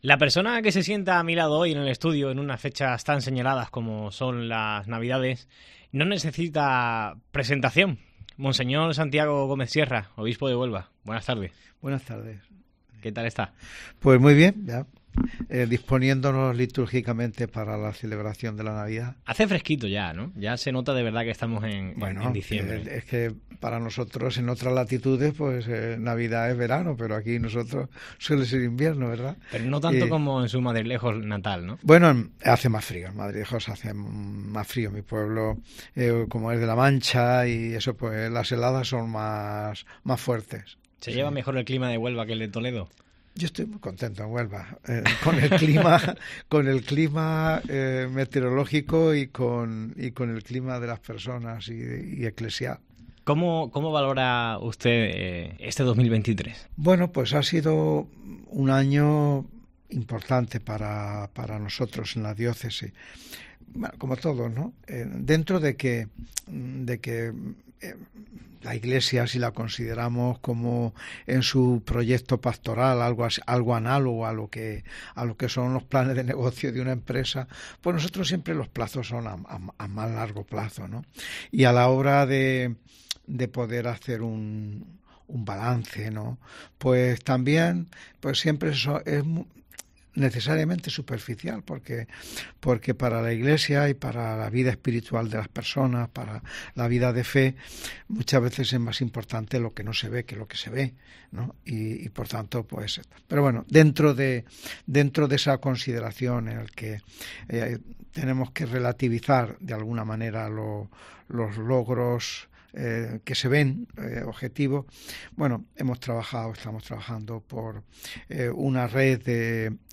AUDIO: Escucha la entrevista completa con Monseñor Santiago Gómez Sierra, obispo de Huelva, que repasa asuntos de actualidad, manda un mensaje...